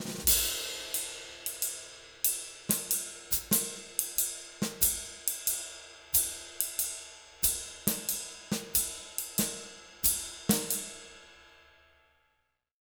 92SWING 03-R.wav